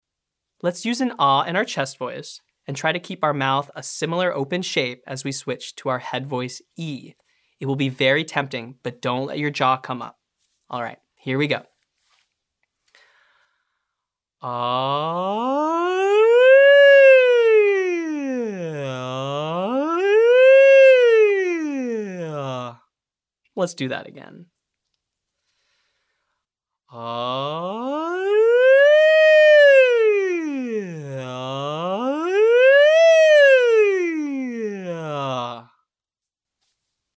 • Wide Siren between registers ( Ahhh to eee)